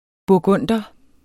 burgunder substantiv, fælleskøn Bøjning -en, -e eller -, -ne Udtale [ buɐ̯ˈgɔnˀdʌ ] Betydninger 1.